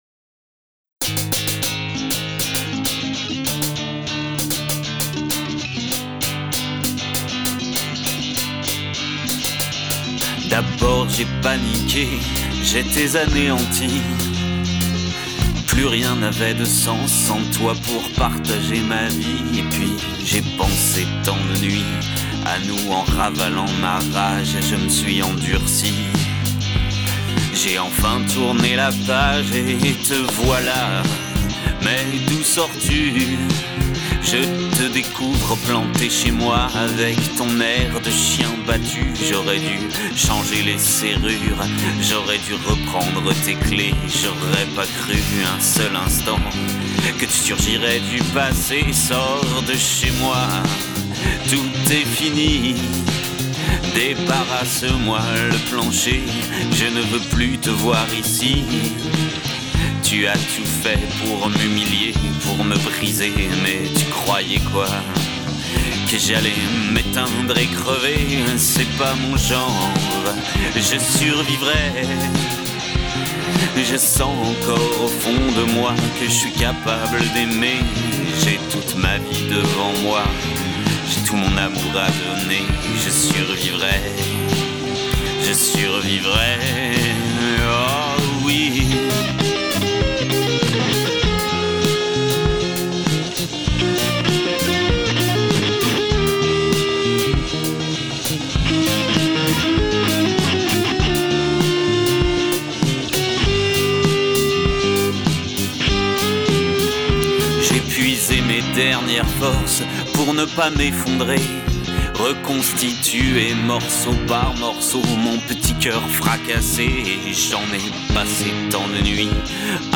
Avec un synth� des ann�es 80